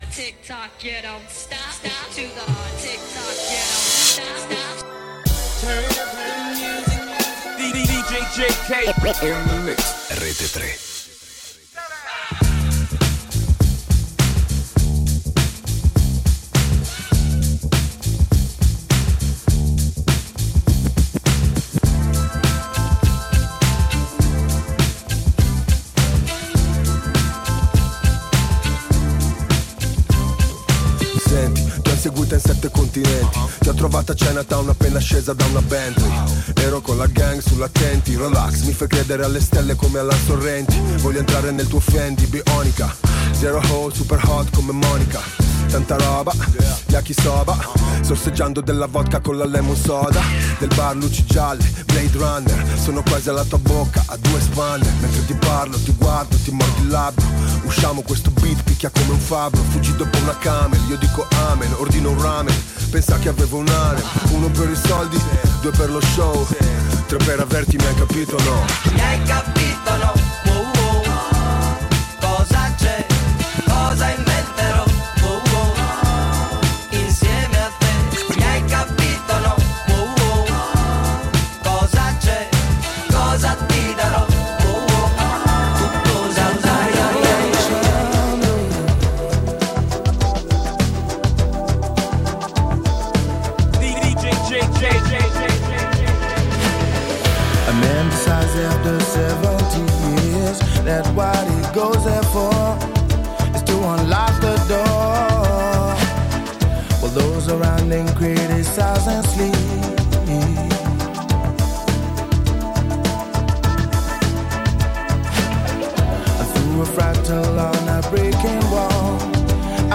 RAP / HIP-HOP